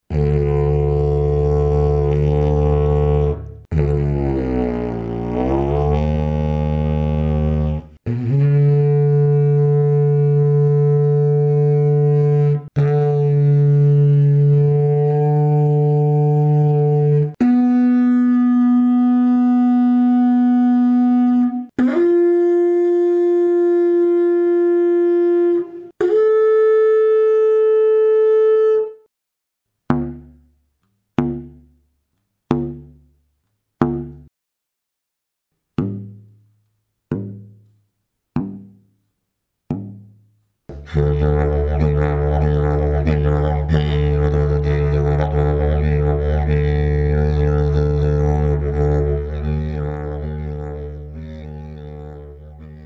Grundton, Ziehbereiche und Overblows bei 24grd C: D2+-0 (Bb1 bis D2+50) // D3 -5 (-50, +20) / Bn3-5 / F4-10 / A4-15 Dg517 is a didgeridoo of my model 049, tuned to D2, with the overblow on the octave D3. All overblows are very close to the planned, calculated pitches. The very stable and easily playable fundamental tone has very effective resonance resistance. Despite the narrow cone shape, the first overblow is on the octave. The shape produces a calmer, less booming fundamental tone that can be easily modulated with voice effects and therefore also microphones are not so easily overloaded.
Fundamental note, pull ranges and overblows at 24� C: D2+-0 (Bb1 to D2+50) // D3 -5 (-50, +20) / Bn3-5 / F4-10 / A4-15 Dg517 Technical sound sample 01